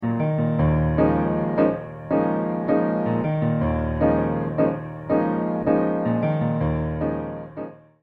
solo piano arrangement